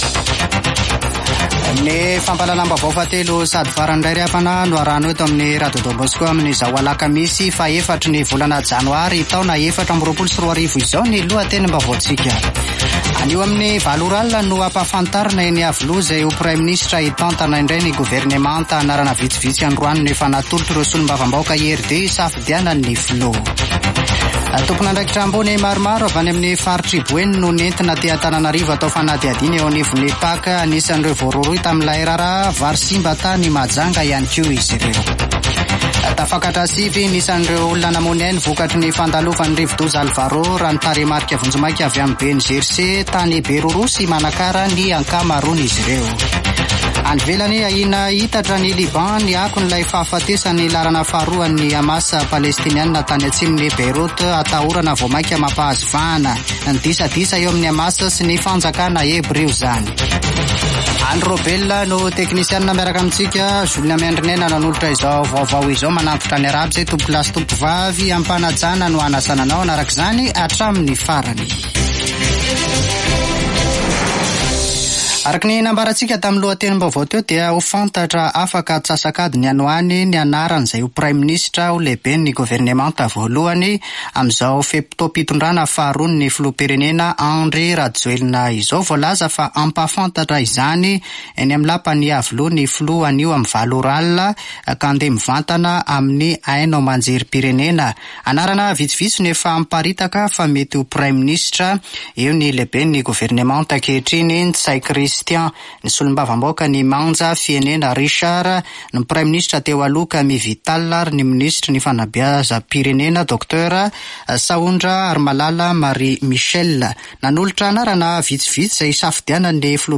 [Vaovao hariva] Alakamisy 4 janoary 2024